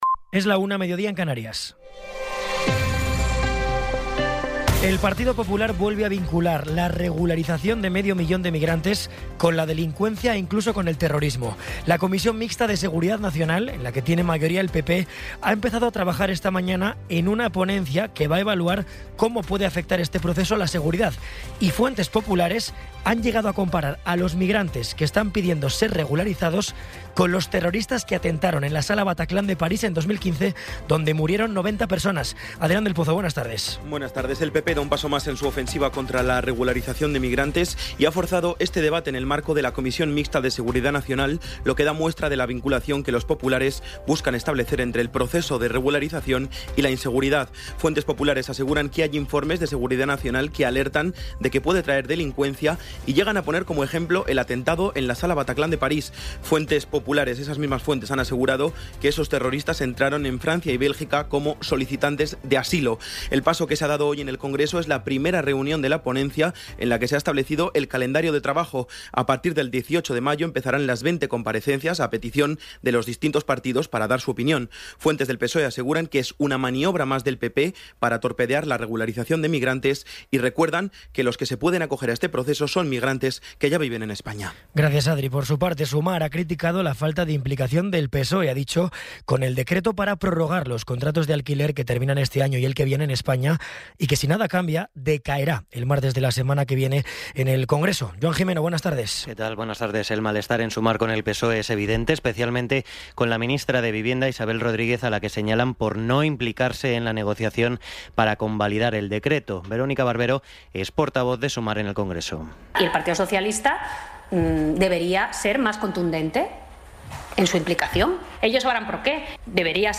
Resumen informativo con las noticias más destacadas del 21 de abril de 2026 a la una de la tarde.